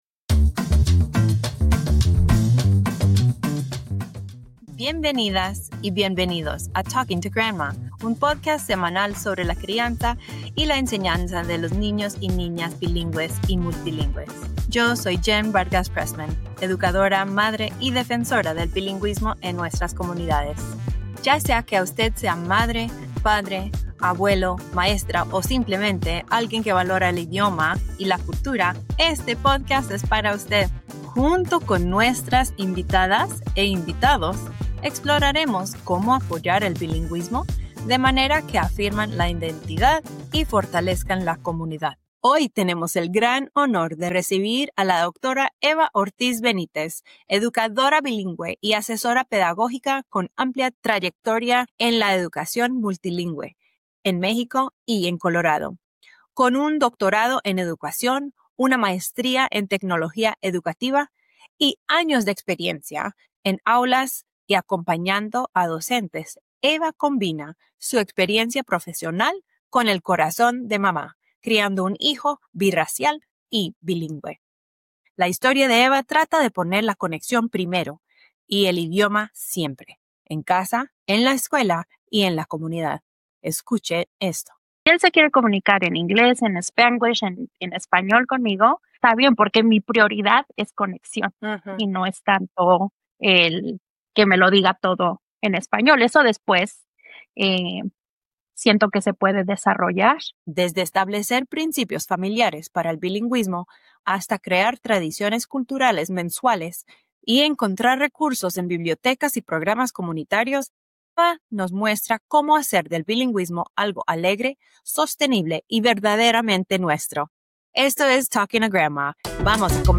If you’re looking for real-life tools, culturally sustaining practices, and encouragement to find—or create—community, this conversation is for you.